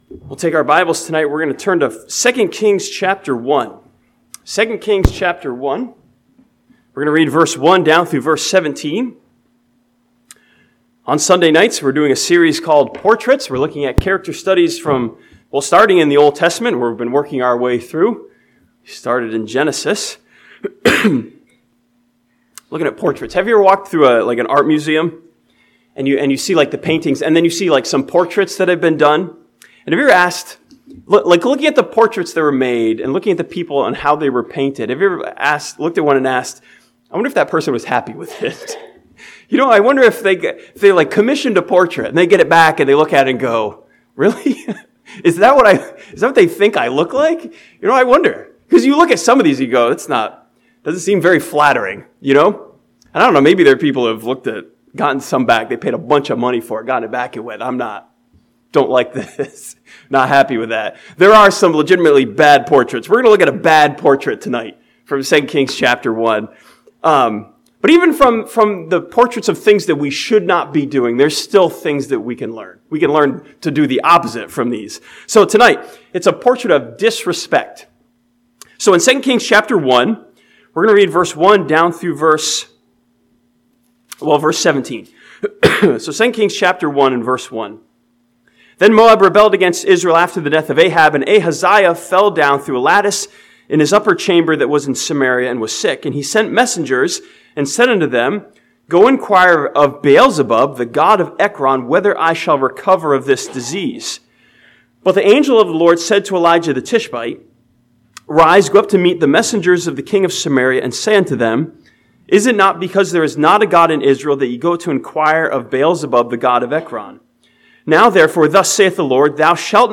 This sermon from 1 Corinthians chapter 4 challenges believers with King Ahaziah as a portrait of disrespect to God and His Word.